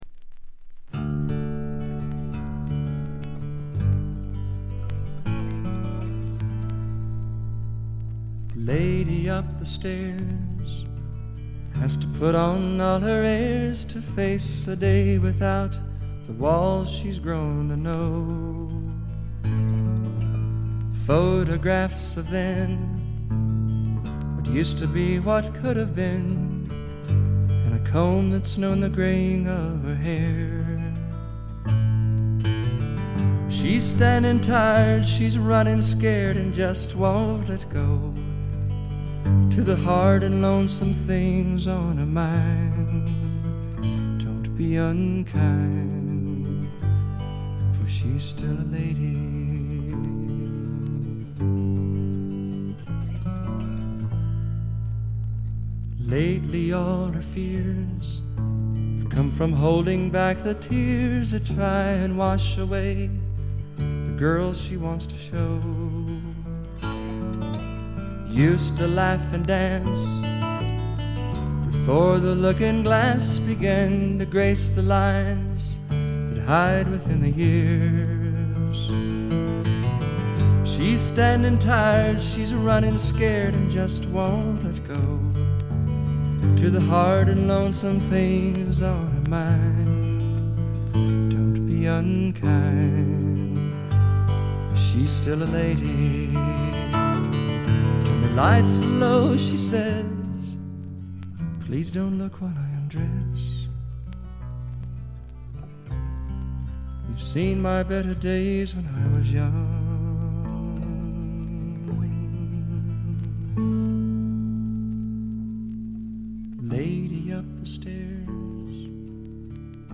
ところどころでバックグラウンドノイズ、チリプチ。目立つノイズはありません。
試聴曲は現品からの取り込み音源です。
Lead Vocals, Acoustic Guitar
Twelve-String Guitar, Harmony Vocals